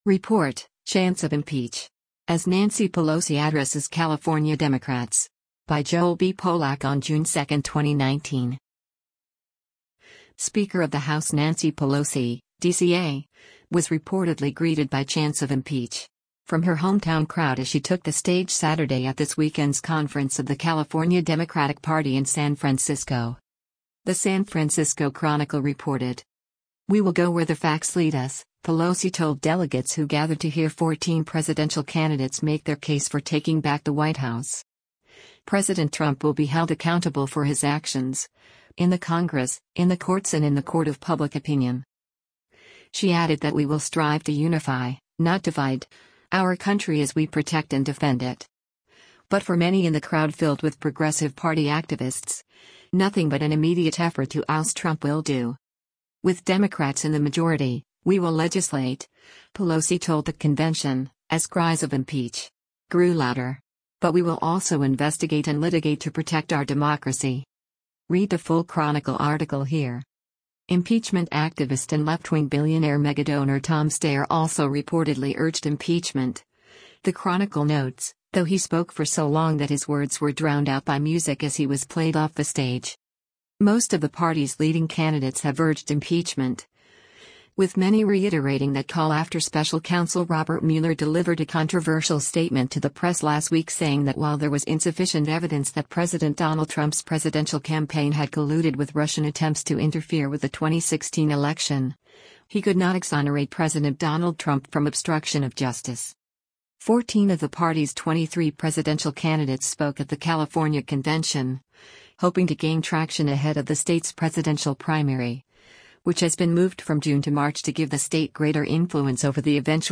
Report: Chants of 'Impeach!' as Nancy Pelosi Addresses California Democrats
Speaker of the House Nancy Pelosi (D-CA) was reportedly greeted by chants of “Impeach!” from her hometown crowd as she took the stage Saturday at this weekend’s conference of the California Democratic Party in San Francisco.
“With Democrats in the majority, we will legislate,” Pelosi told the convention, as cries of “Impeach!” grew louder.